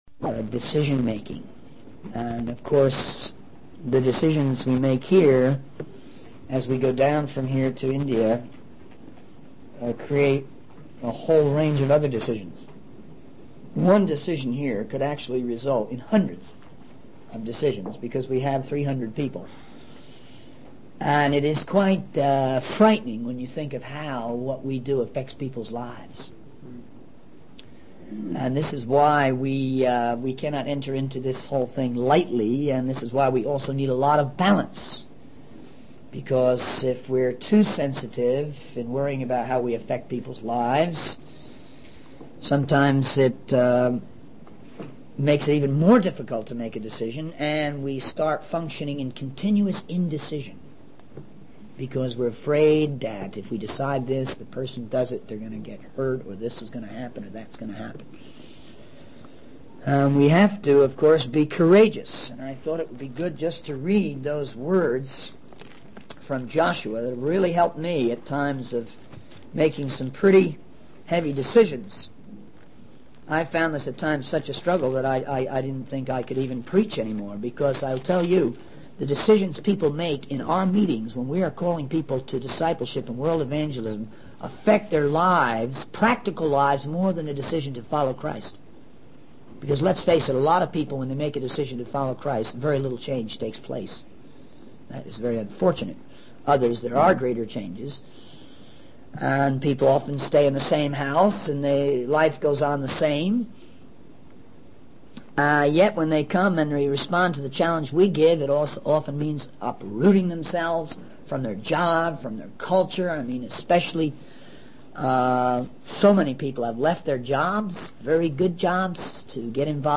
In this sermon, the speaker emphasizes the importance of understanding and balancing different perspectives. He encourages the audience not to give up and reminds them of God's promise to be with them. The speaker also discusses the need to consider whether a decision will glorify Christ and the practical aspects involved.